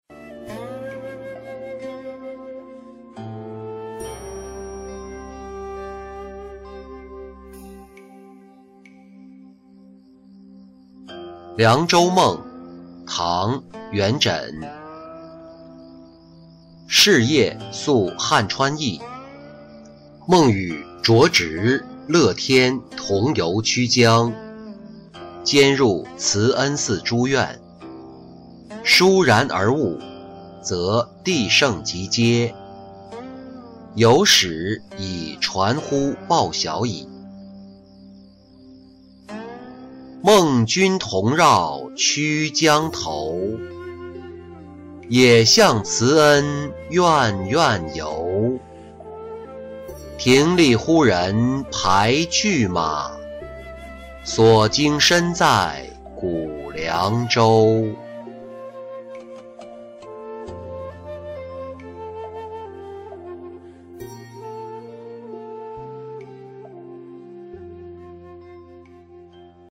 寄令狐郎中-音频朗读